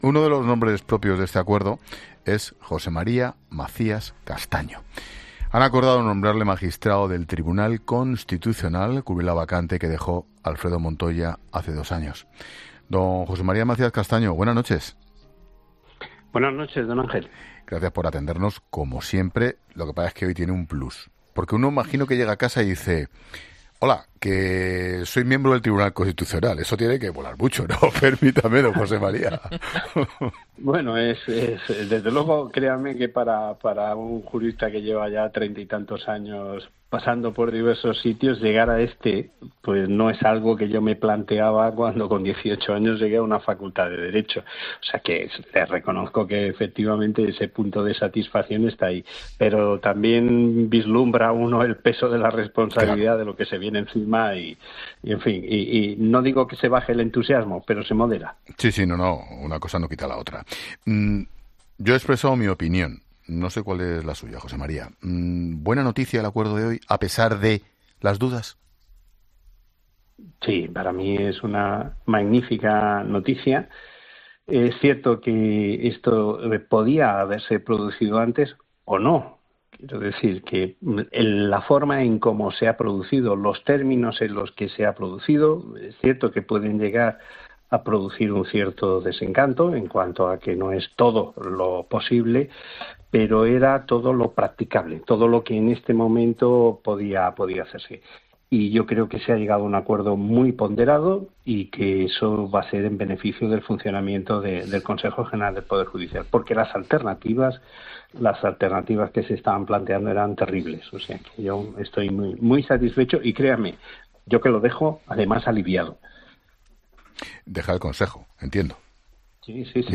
El magistrado, nombrado miembro del TC este martes, asegura en La Linterna que deja el CGPJ "aliviado" tras un "acuerdo ponderado"